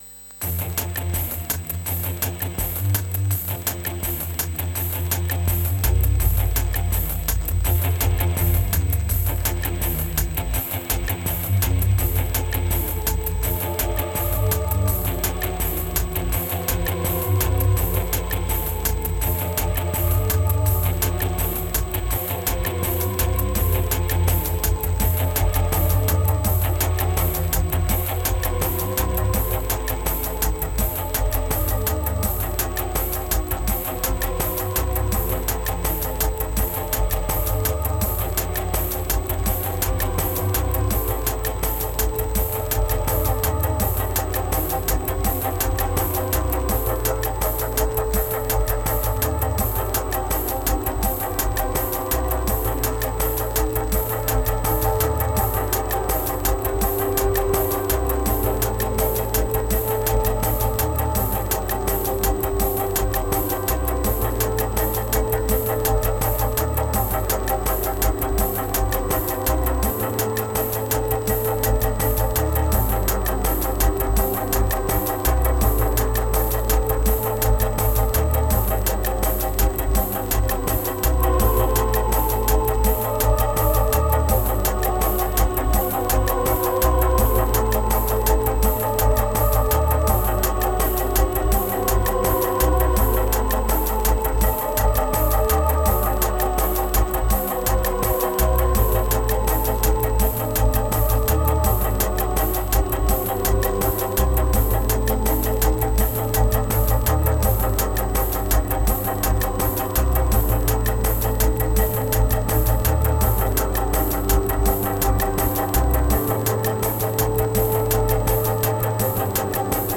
1526📈 - 71%🤔 - 83BPM🔊 - 2022-09-24📅 - 398🌟